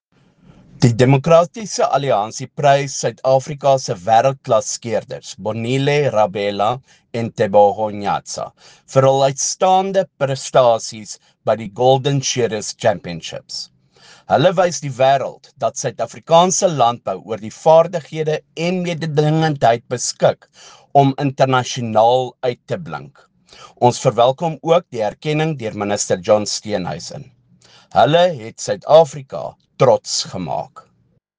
Afrikaans soundbite by Beyers Smit MP